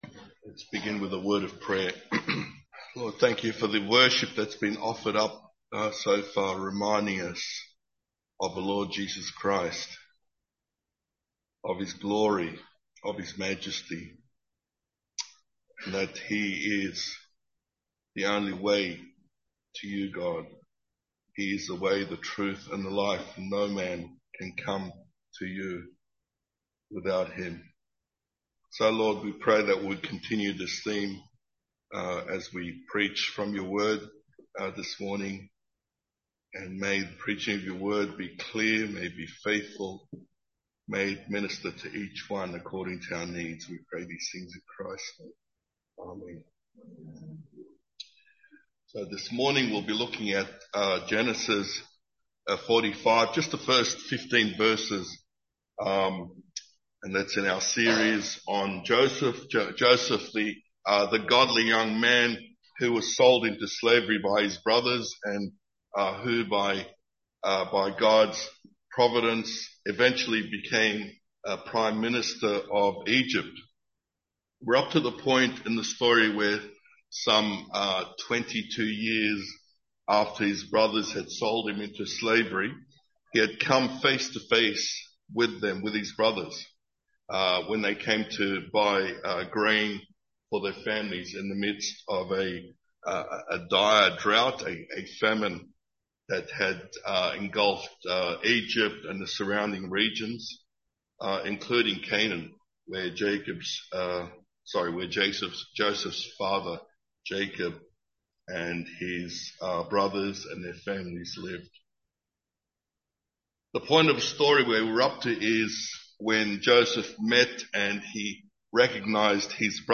Sermons , The Life of Joseph